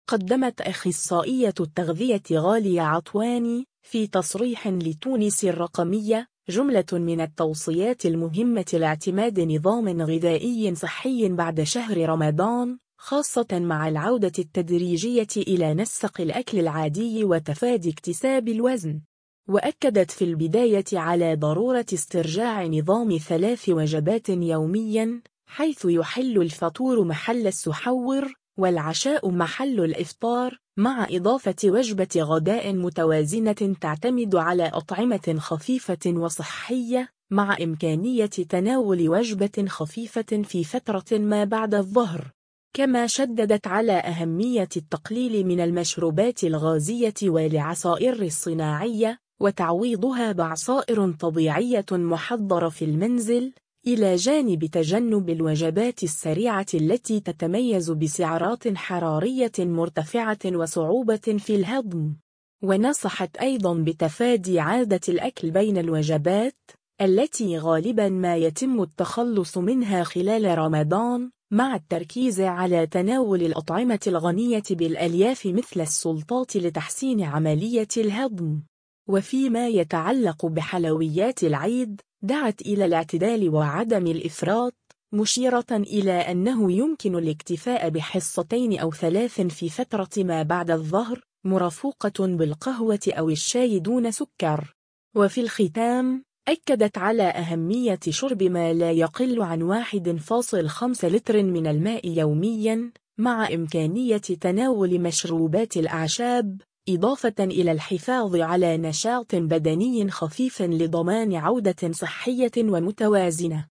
أخصائية التغذية
تصريح